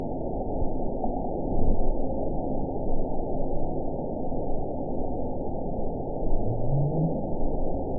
event 917064 date 03/17/23 time 19:48:48 GMT (2 years, 9 months ago) score 8.87 location TSS-AB03 detected by nrw target species NRW annotations +NRW Spectrogram: Frequency (kHz) vs. Time (s) audio not available .wav